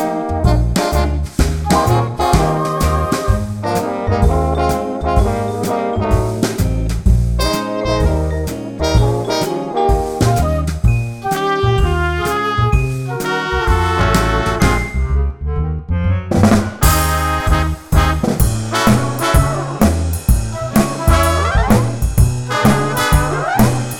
no Backing Vocals Christmas 2:23 Buy £1.50